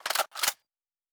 pgs/Assets/Audio/Sci-Fi Sounds/Weapons/Weapon 15 Reload 1.wav at master
Weapon 15 Reload 1.wav